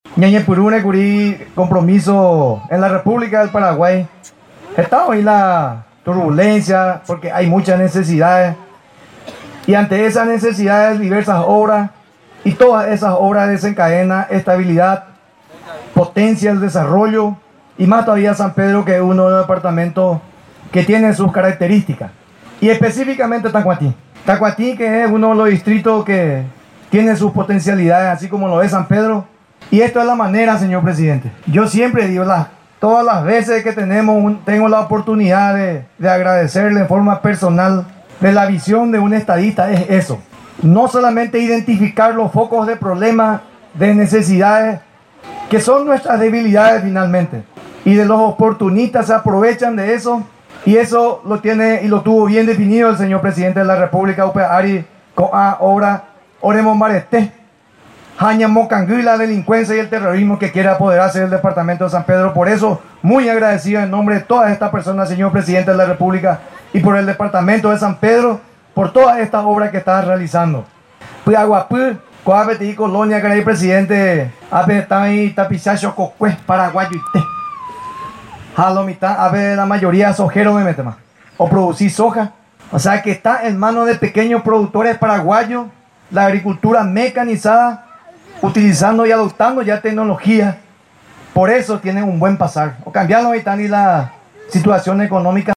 Con la finalidad de beneficiar a unas 19.000 familias de los distritos de Py´aguapy y de Tacuatí, del departamento de San Pedro, el Gobierno Nacional, habilitó dos Unidades de Salud Familiar, en un acto que tuvo la presencia del presidente de la República, Mario Abdo Benítez.